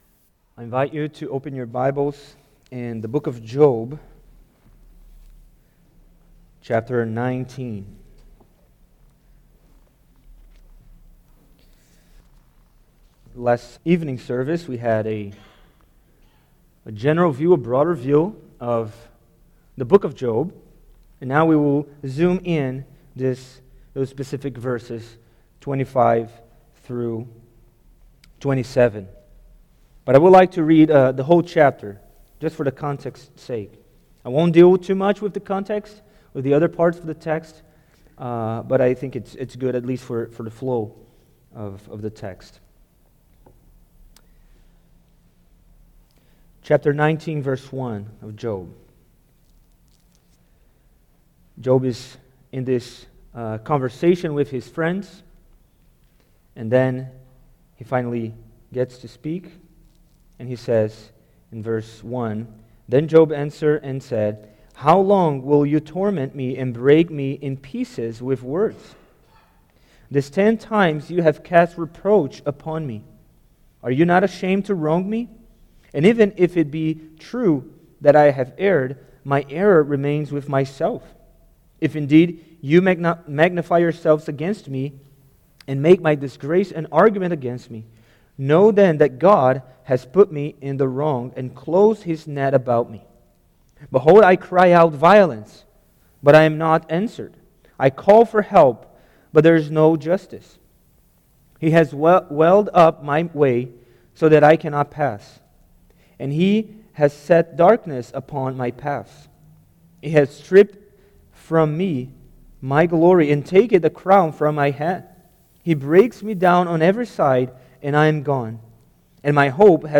Redeemer Series Various Sermons Book Job Watch Listen Save In Job 19:25-27, Job expresses his confidence and faith in the living Savior that will redeem him.